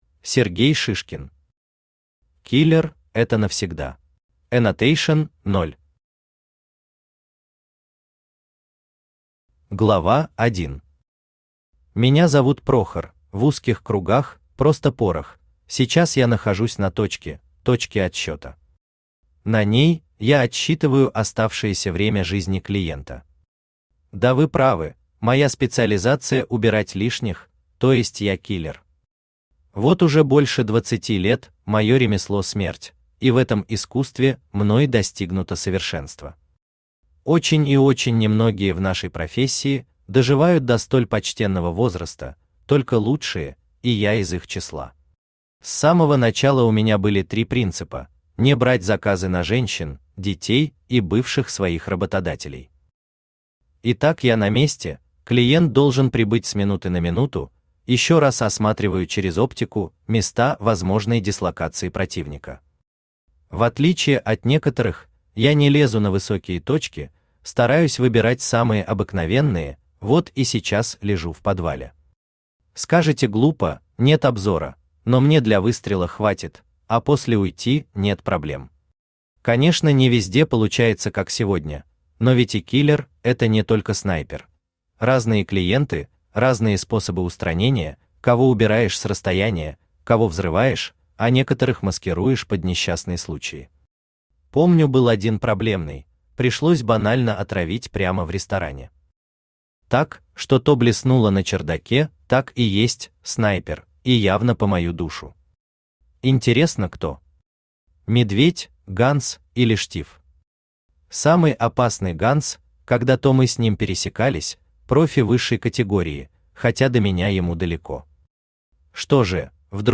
Аудиокнига Киллер – это навсегда | Библиотека аудиокниг
Aудиокнига Киллер – это навсегда Автор Сергей Шишкин Читает аудиокнигу Авточтец ЛитРес.